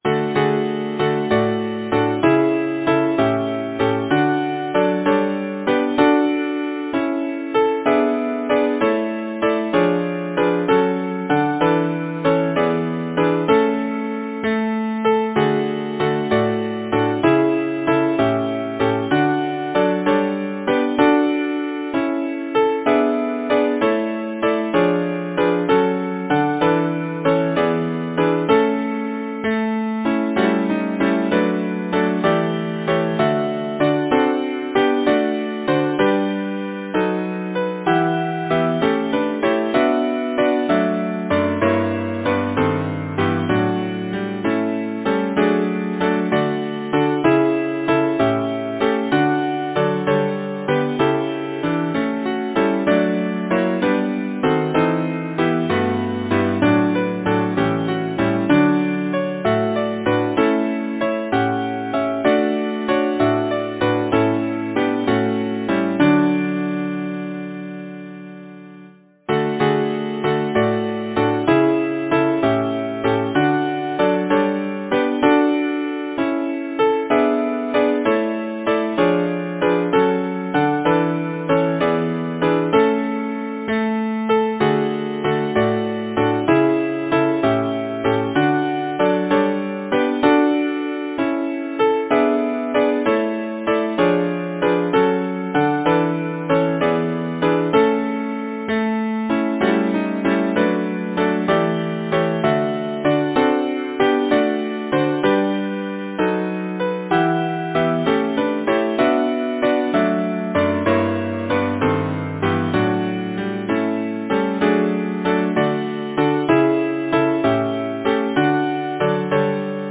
Title: Springtime Composer: Samuel Reay Lyricist: Clarence Austincreate page Number of voices: 4vv Voicing: SATB Genre: Secular, Partsong
Language: English Instruments: A cappella